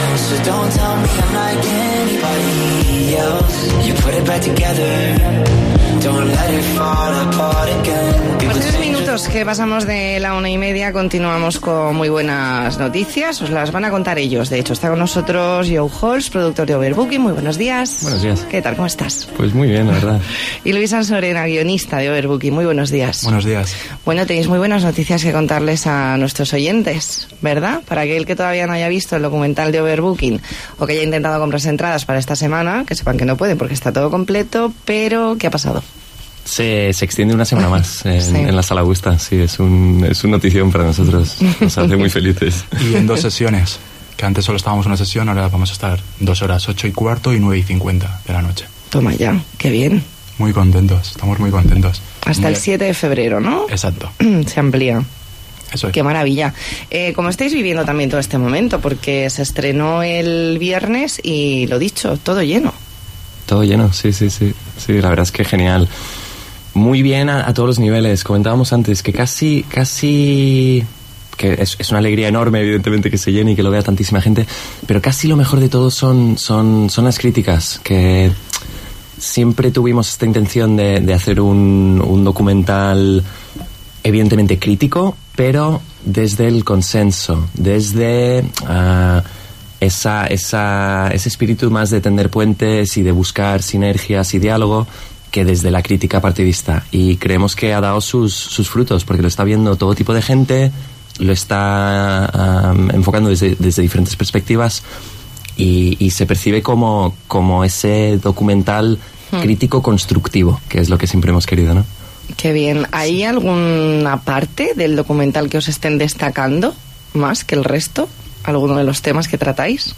Entrevista en 'La Mañana en COPE Más Mallorca', martes 29 de enero de 2019.